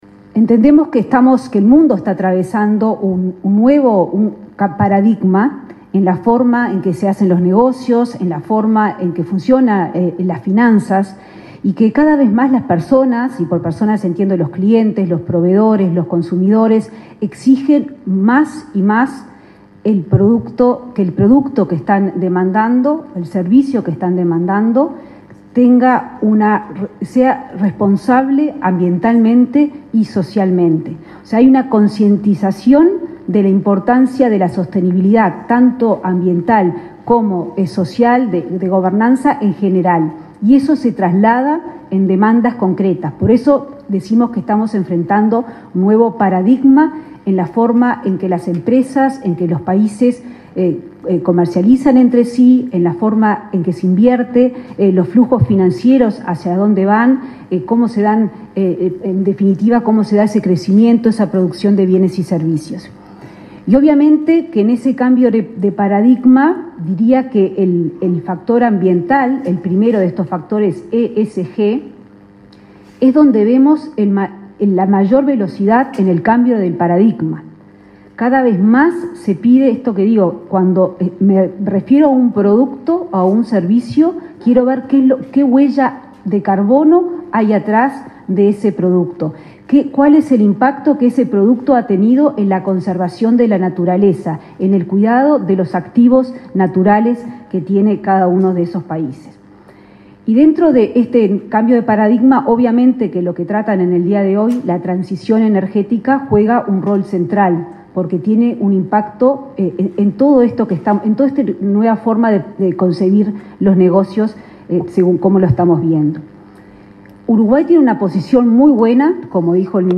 Disertación de la ministra de Economía, Azucena Arbeleche
La ministra de Economía, Azucena Arbeleche, disertó en una conferencia sobre el desafío de las transiciones energéticas, organizada por Ancap y Arpel